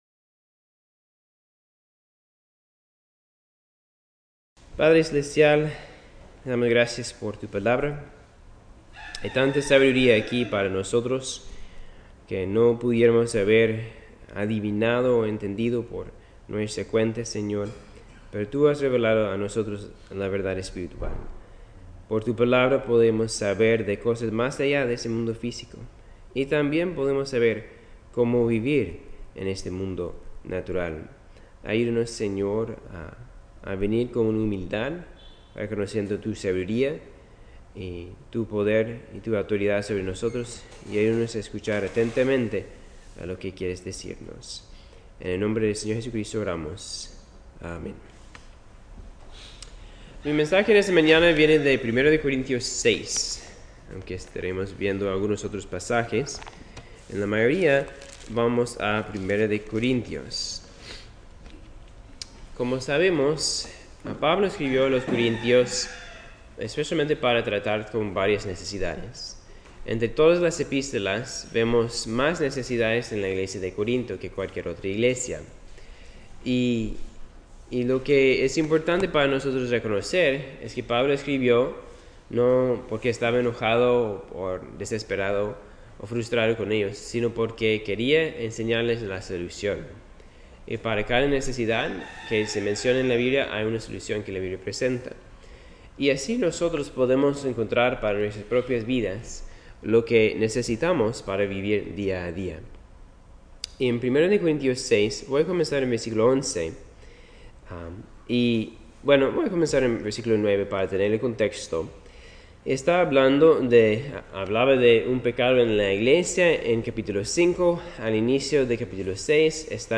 Servicio de capilla